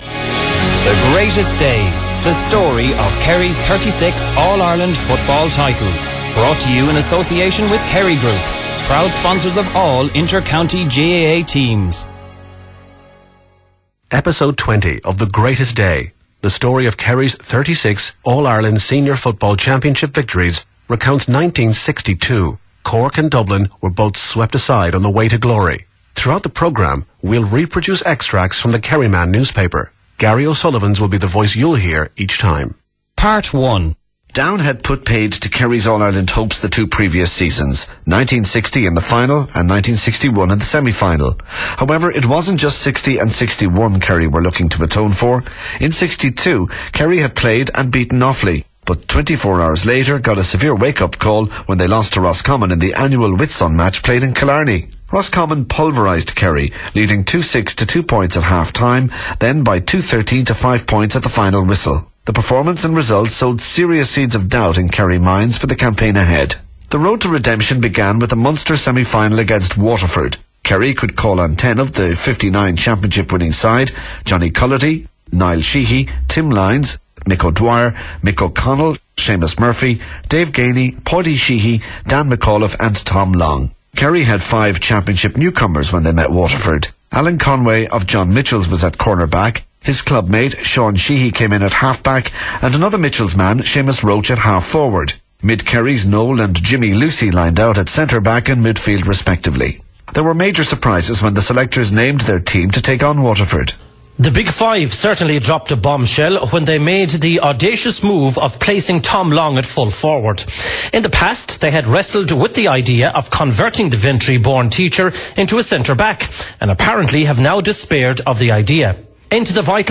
The Greatest Day Back to The Greatest Day The Greatest Day - AI Title No. 20 - 1962 Recorded: 2009 Length: 39mins play pause stop min volume max volume A 36 part series documenting the story of Kerry's All Ireland Senior Football Championship victories.